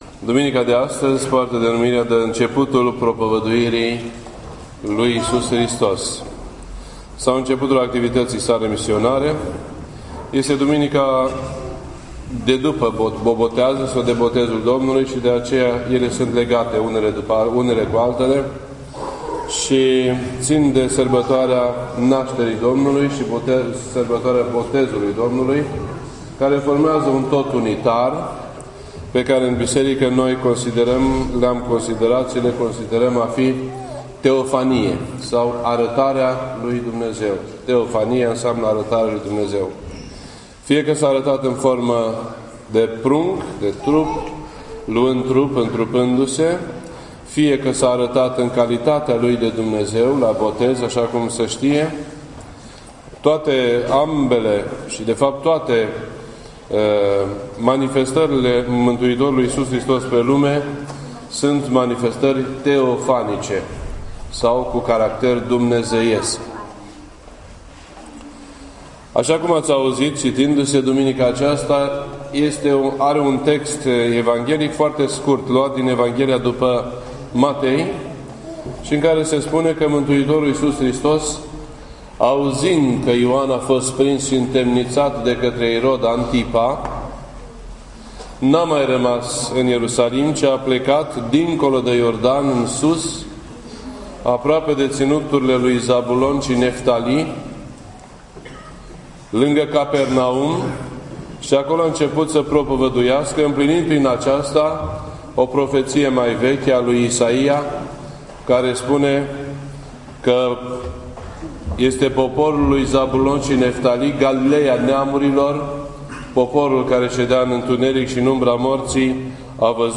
Predici ortodoxe in format audio